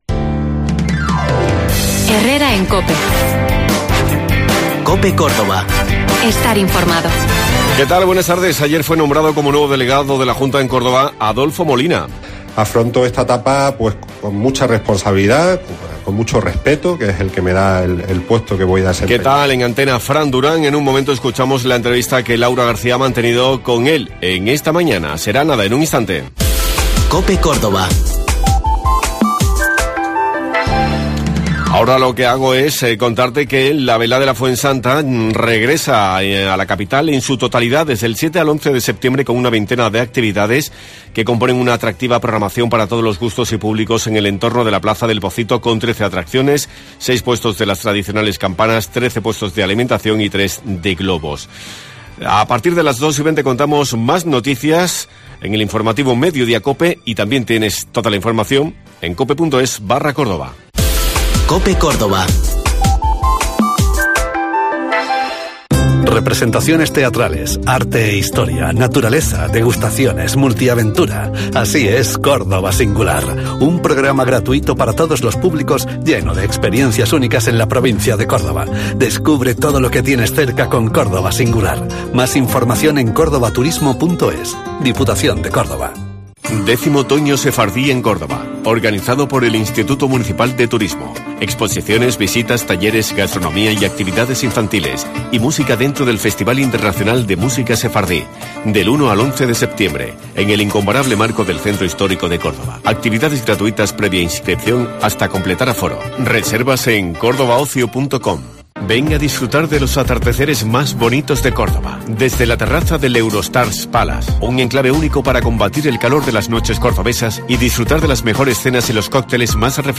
Adolfo Molina se ha convertido en el nuevo delegado de la Junta en Córdoba. En COPE hemos mantenido una entrevista con él después de su toma de posisión.